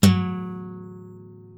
Tercera cuerda de una guitarra
cordófono
guitarra
sol